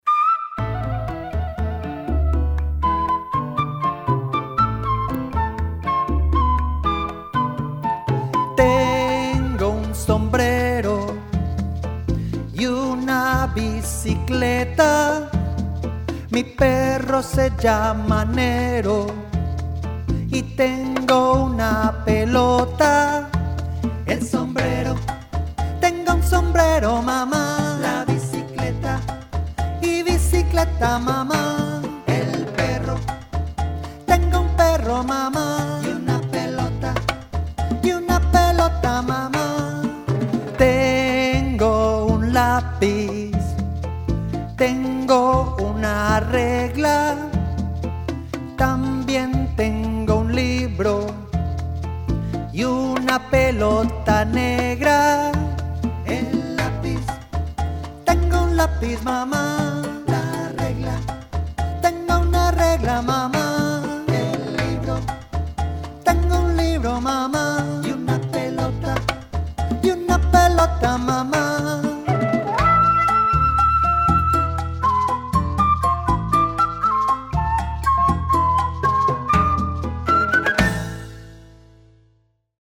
Other resources Poster - My things (A3 pdf 250kb) Flash cards - text (pdf 50kb) Flash cards - pictures (pdf 90kb) Song: Mis cosas * (mp3 1.4mb) Song lyrics (pdf 65kb)